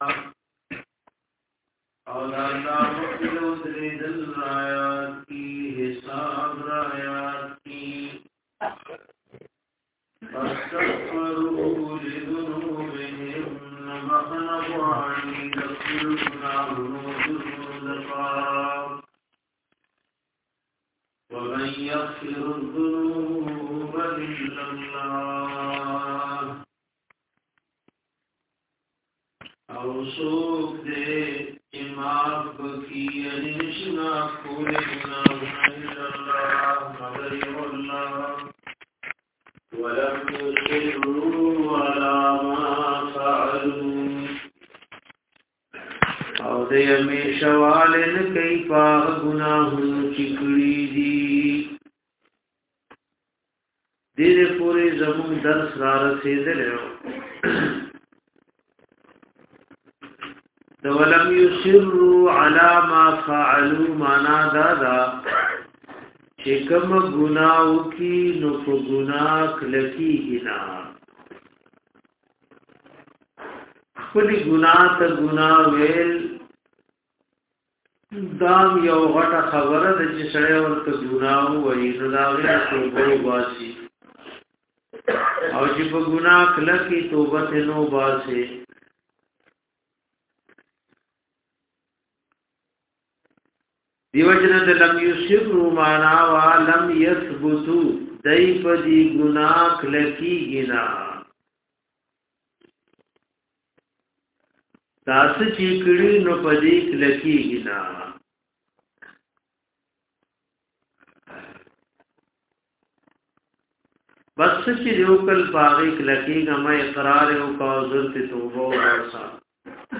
DARSE E QURAN